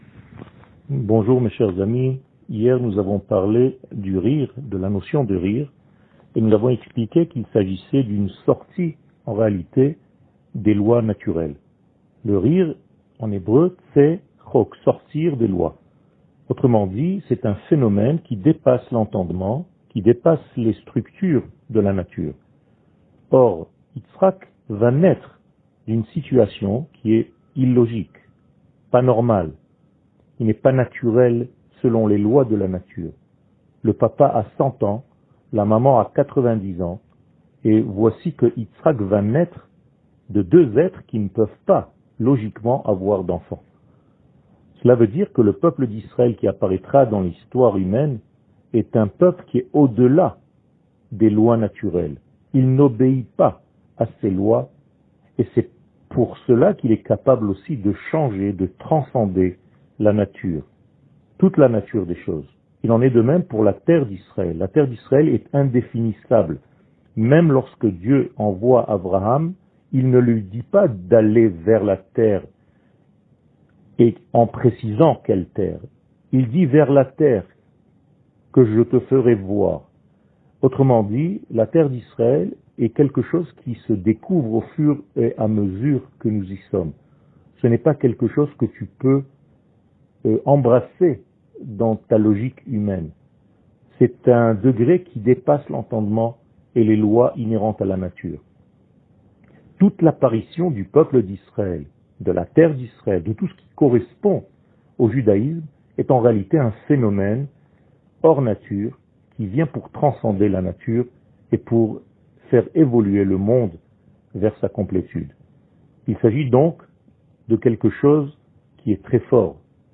שיעור מ 02 נובמבר 2020